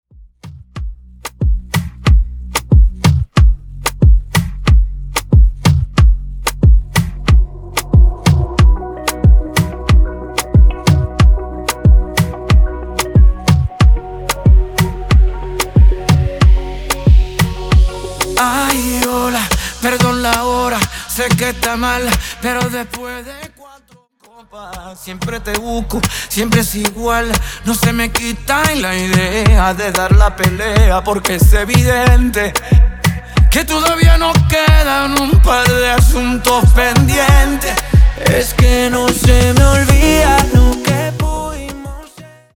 Extended Dirty Intro Acapella